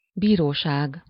Ääntäminen
IPA: [kuʁ]